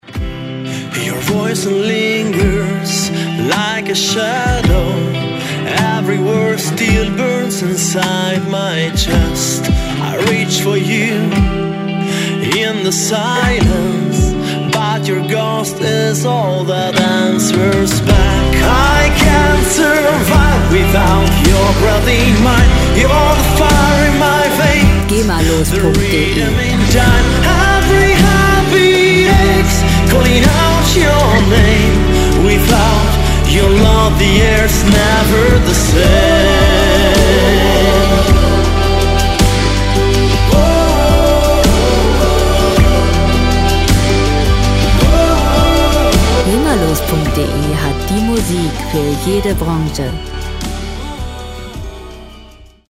im triolischen Pop-Rock-Gewand